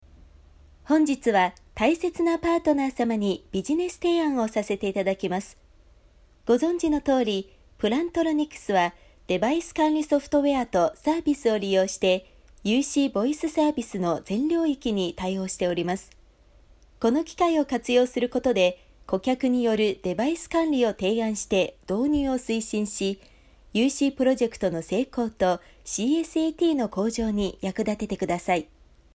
• 5Japanese Female No.2
Gentle Magnetic